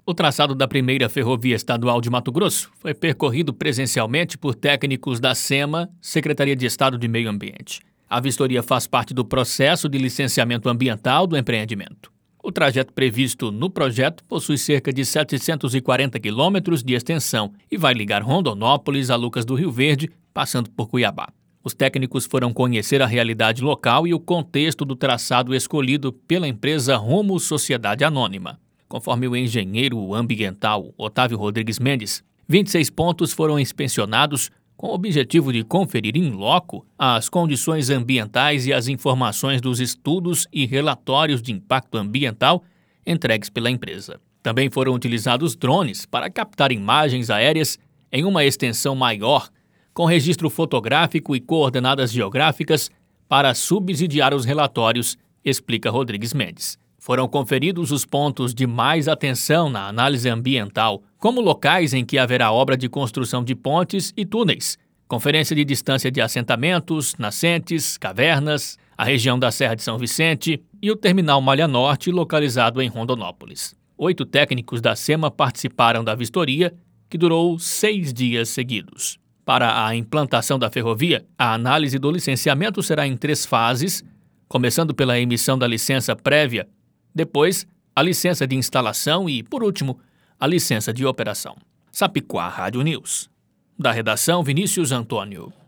Boletins de MT 07 mar, 2022